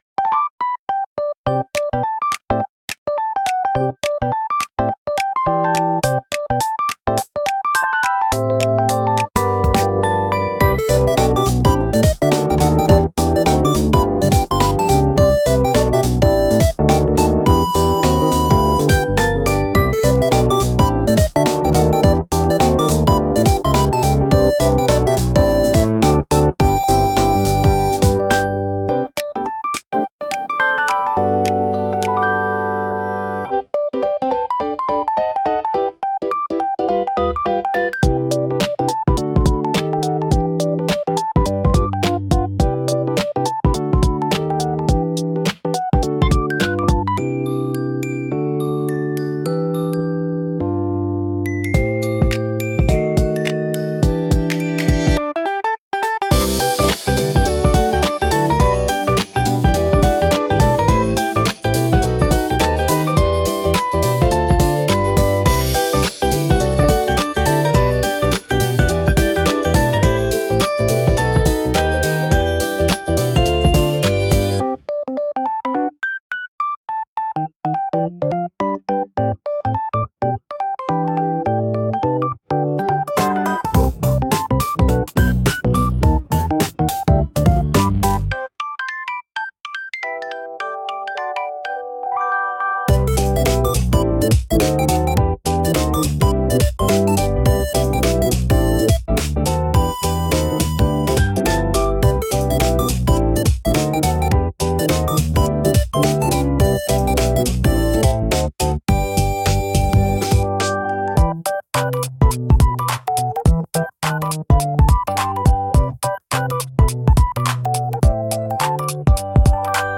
SUNO AI を使用して制作しています
かわいいピコピコBGM